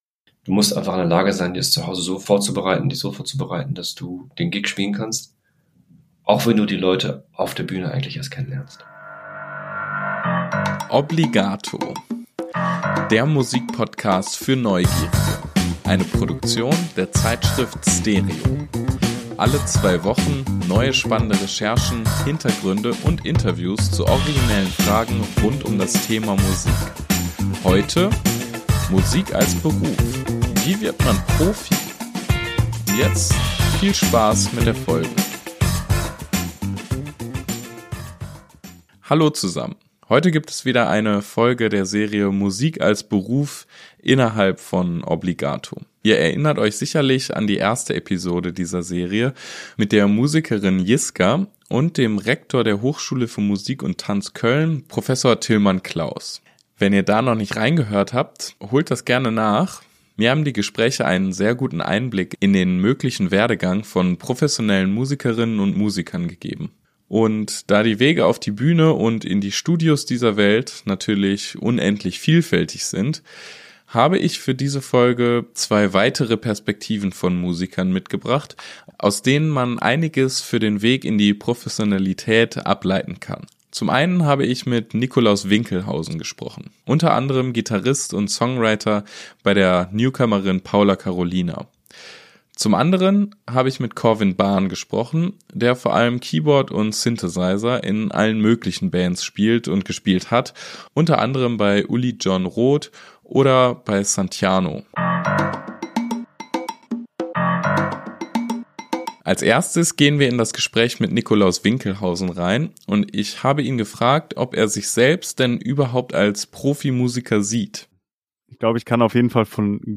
Beschreibung vor 2 Jahren Die Wege auf die Bühnen und in die Studios dieser Welt sind vielfältig. In der zweiten Folge der Obligato-Serie „Musik als Beruf“ werden deshalb zwei weitere Perspektiven von Musikern vorgestellt. Aus den interessanten Interviews kann man einiges für den Weg in die musikalische Professionalität ableiten.